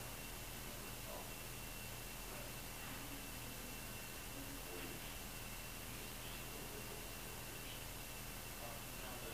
Female Humming
This EVP was captured in an upstairs bedroom.